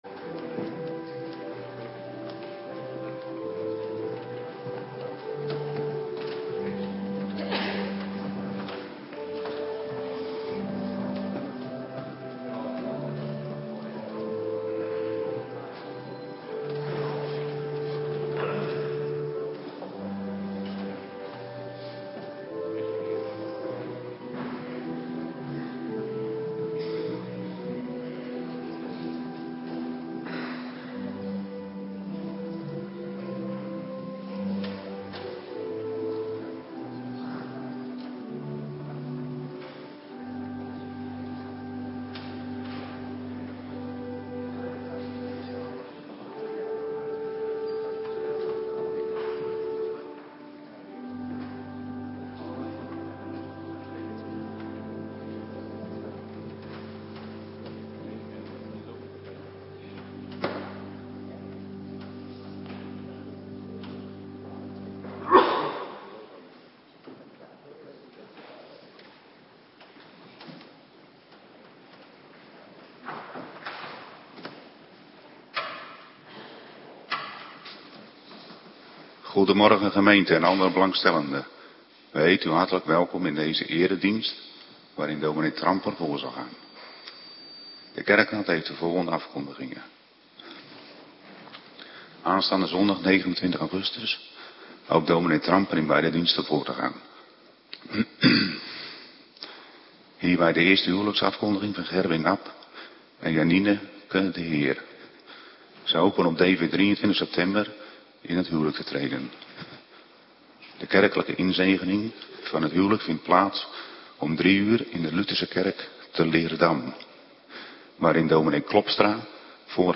Morgendienst - Cluster B
Locatie: Hervormde Gemeente Waarder